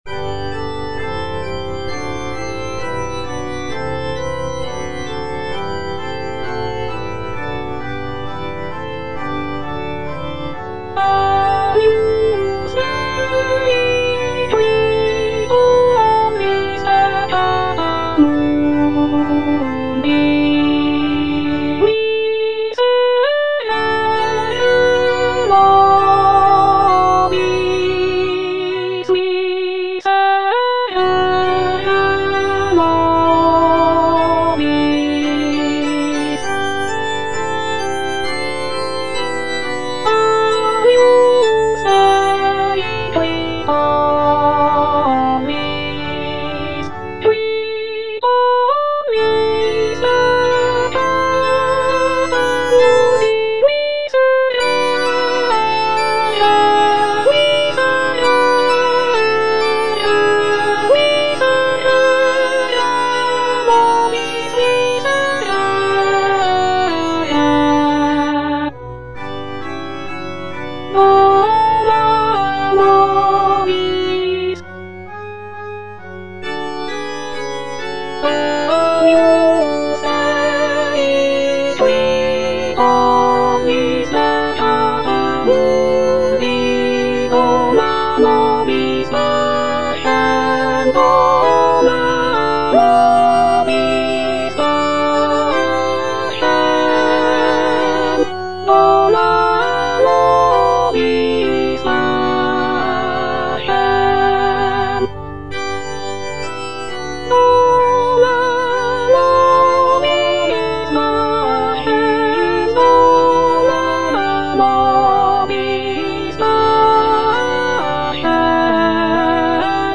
G. FAURÉ, A. MESSAGER - MESSE DES PÊCHEURS DE VILLERVILLE Agnus Dei (soprano I) (Emphasised voice and other voices) Ads stop: auto-stop
The composition is a short and simple mass setting, featuring delicate melodies and lush harmonies.